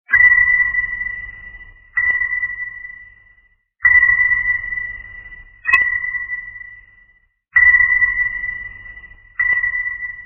U Boot Sonar Ping klingelton kostenlos
Kategorien: Soundeffekte